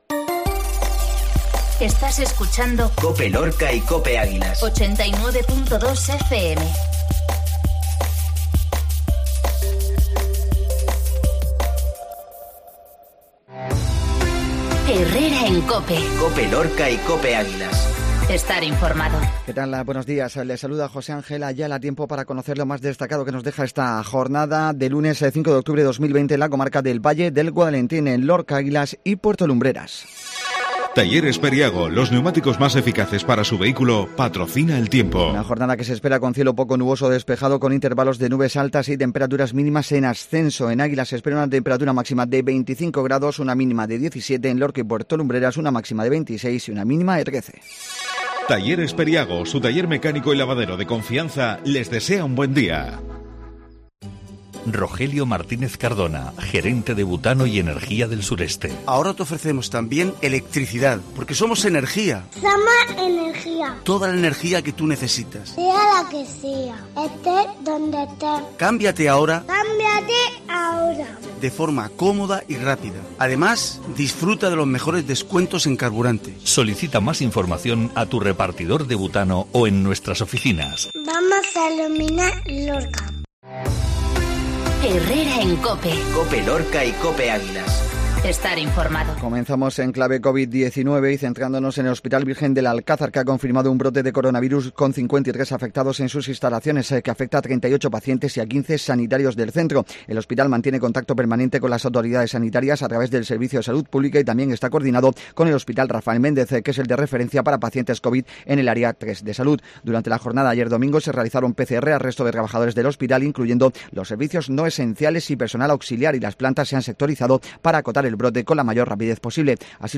INFORMATIVO MATINAL LUNES 5